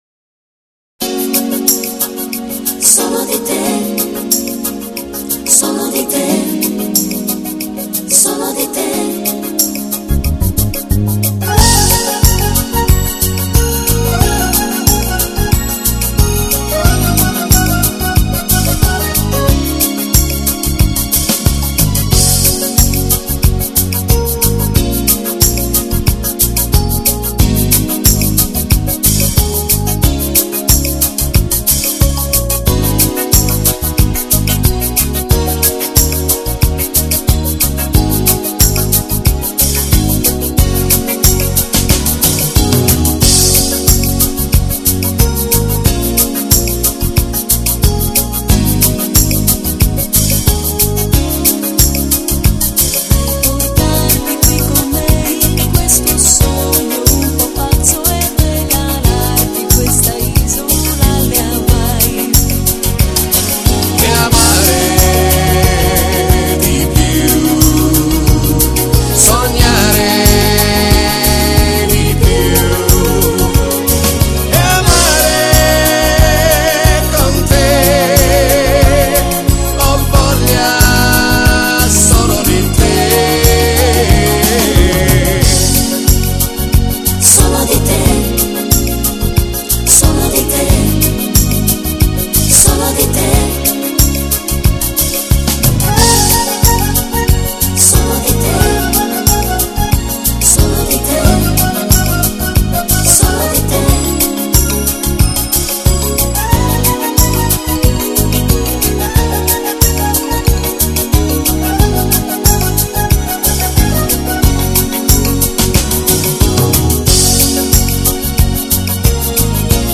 Genere: Reggae